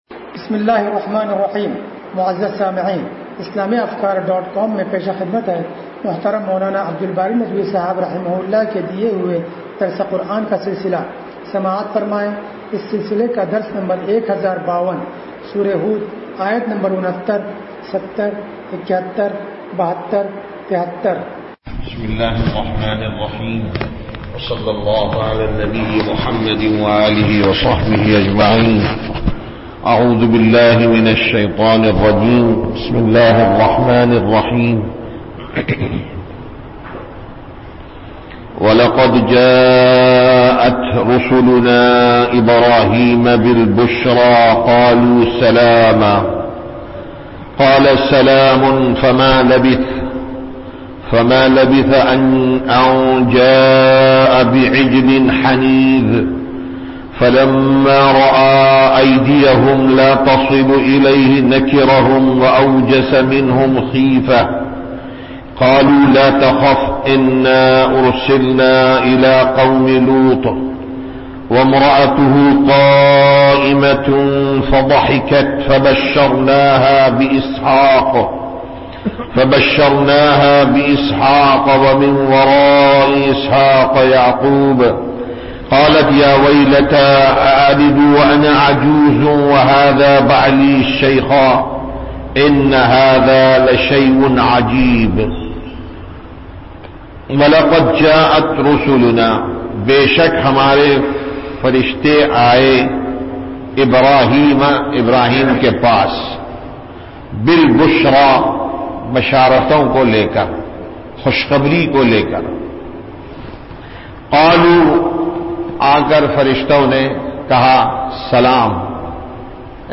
درس قرآن نمبر 1052
درس-قرآن-نمبر-1052.mp3